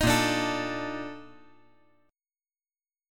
BbM11 Chord
Listen to BbM11 strummed